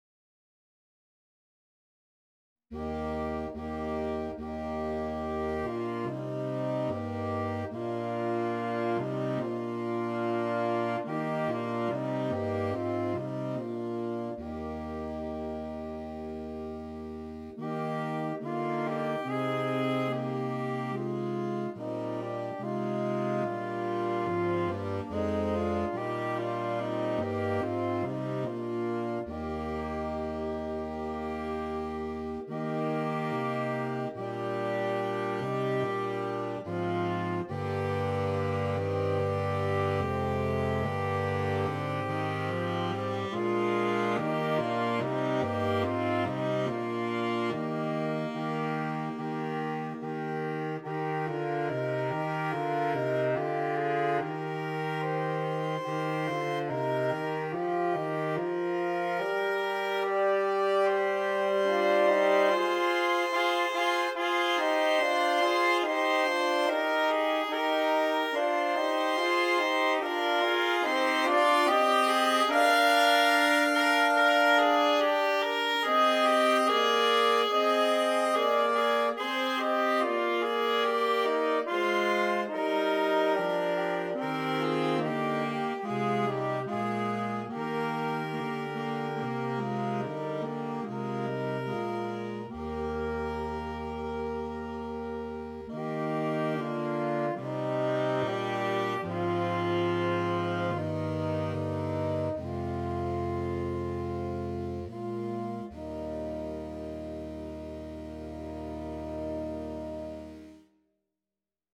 Saxophone Quartet (SATB or AATB)